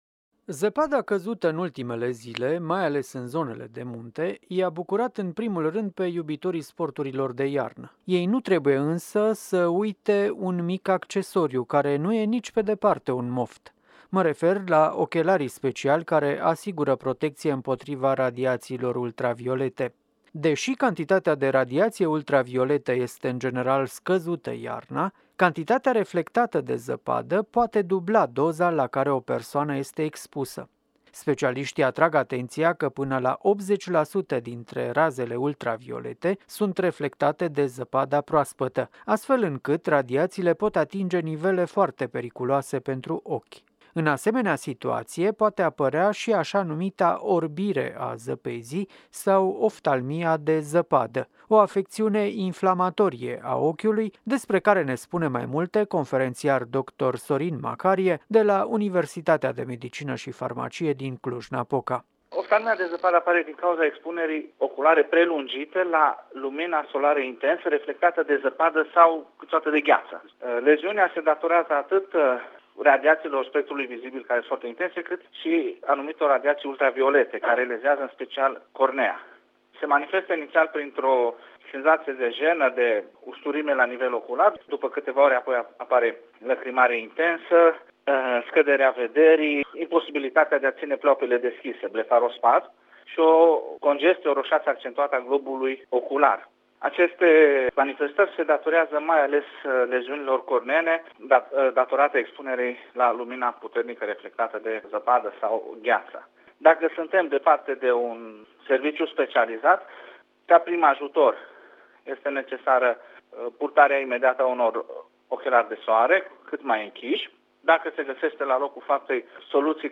vorbește în această dimineață la radio